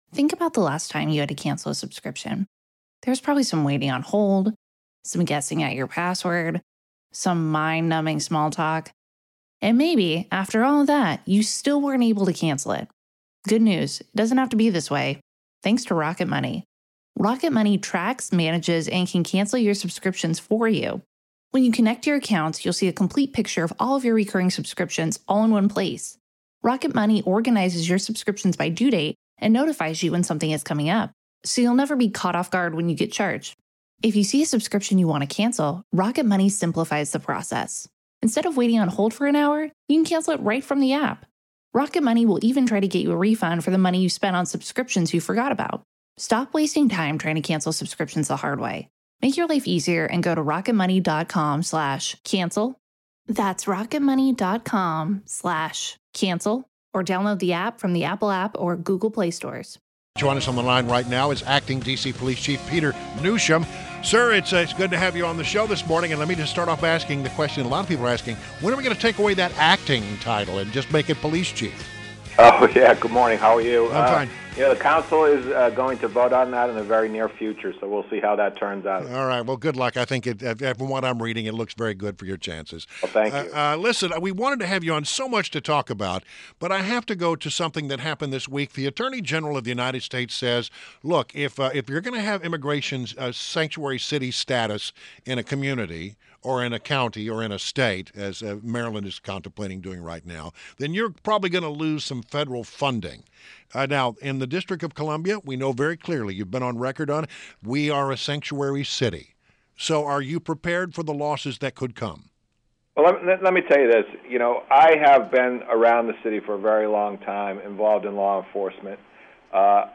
WMAL Interview - Chief Peter Newsham - 03.29.17